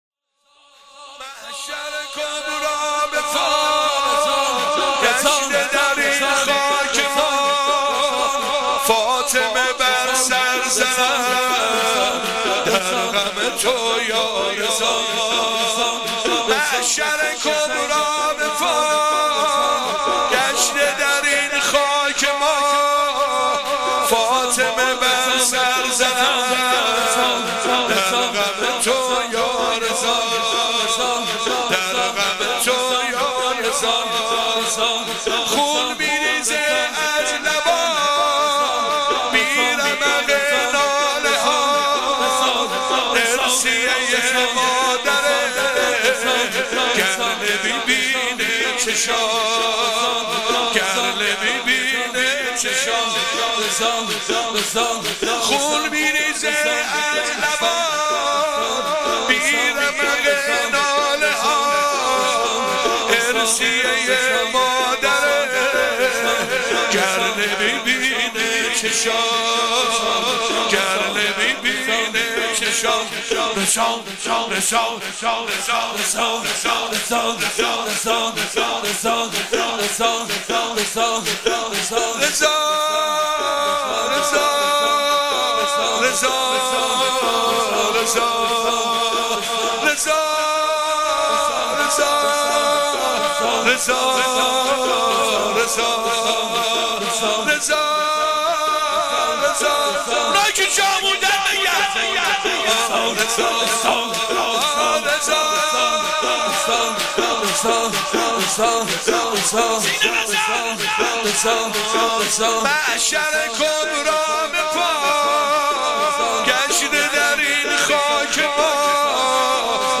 shoor emam reza  saf,94.mp3